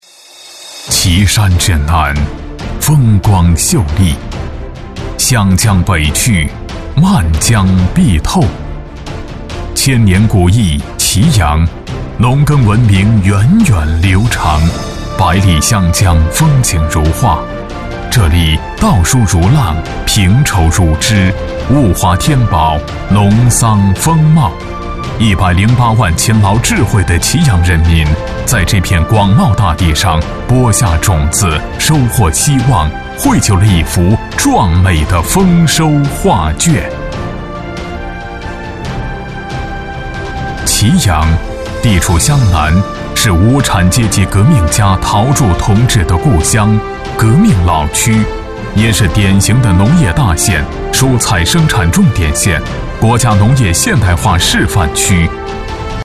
样音试听 - 红樱桃配音-真咖配音-500+真人配音老师 | 宣传片汇报纪录动画英文粤语配音首选平台
红樱桃配音，真咖配音官网—专业真人配音服务商！